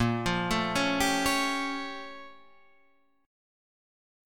A# Minor Major 13th